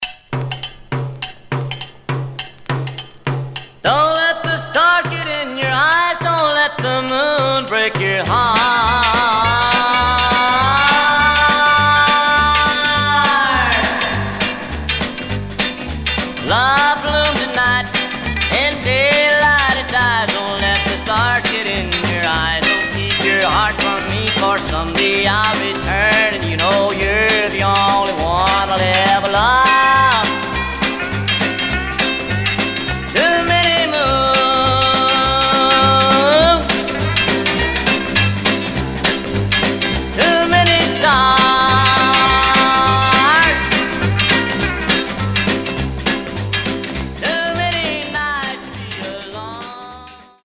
Teen